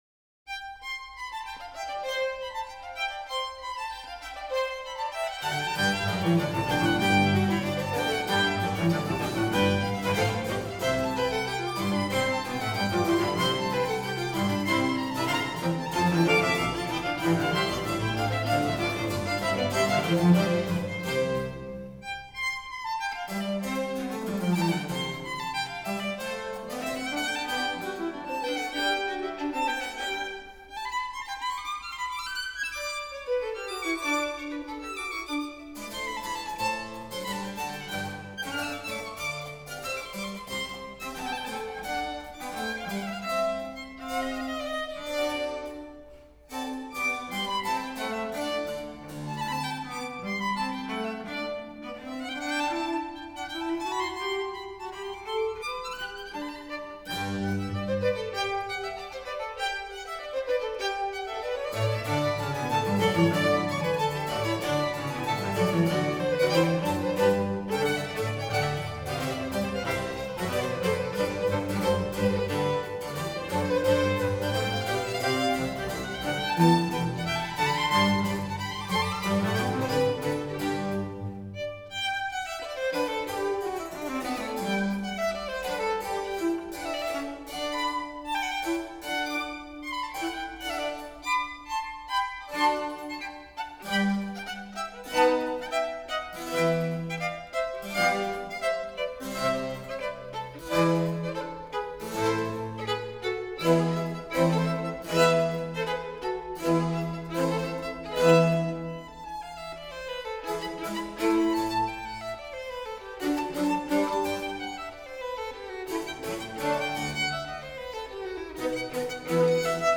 Violin Concerto in C - d.2 - Allegro